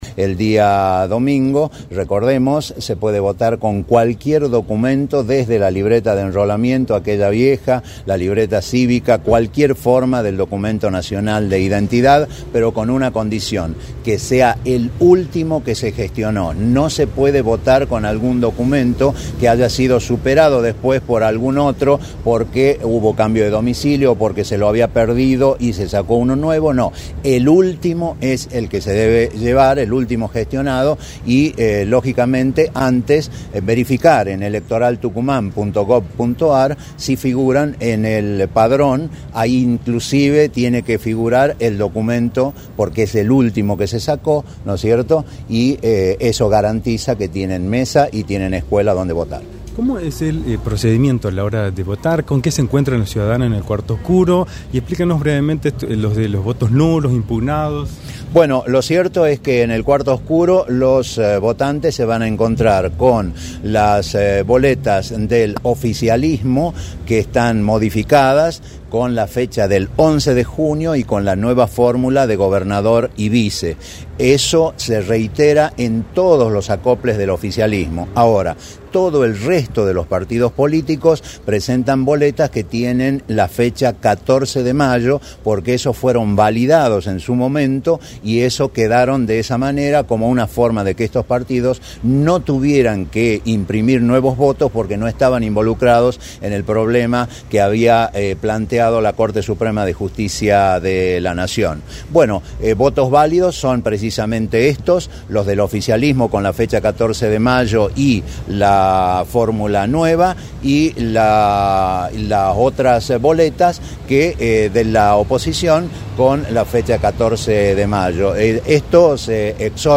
en entrevista para “La Mañana del Plata”, por la 93.9.